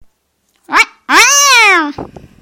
Mikrowellen Katze